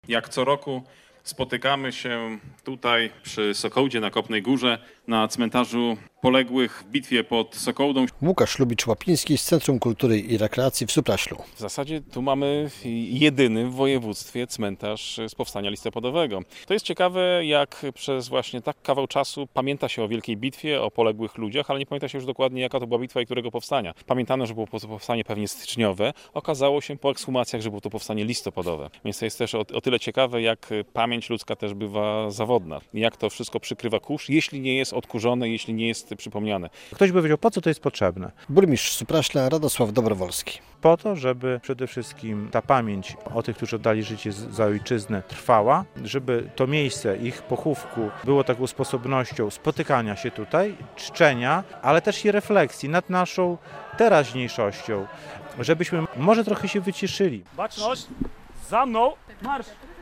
Rocznica bitwy pod Sokołdą - relacja
To okazja do spotkania, do przypominania o rzeczach ważnych, okazja do refleksji i wyciszenia - mówił burmistrz Supraśla Radosław Dobrowolski.